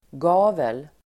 Ladda ner uttalet
gavel substantiv, gable Uttal: [g'a:vel] Böjningar: gaveln, gavlar Definition: kortsida på ett hus (the side of a house that is under the end of a pitched roof) Idiom: på vid gavel ("helt öppen") (wide open) Sammansättningar: västgavel (western side of a house), gavelfönster (gable window)